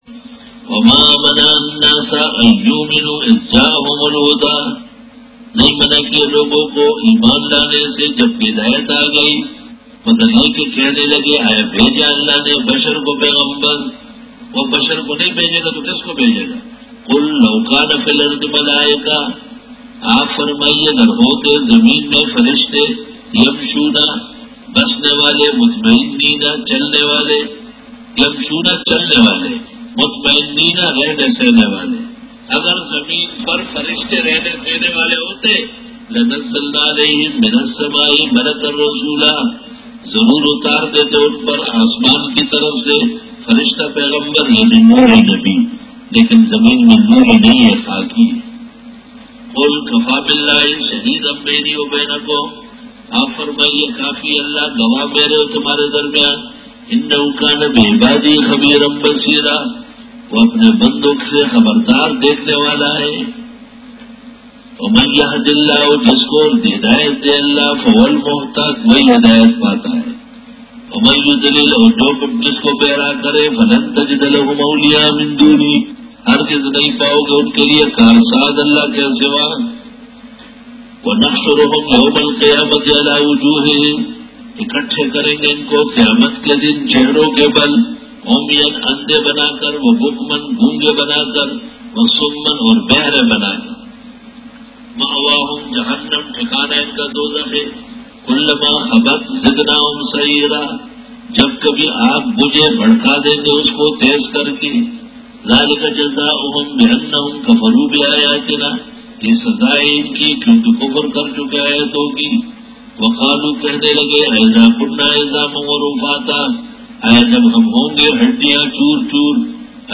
Dora-e-Tafseer 2011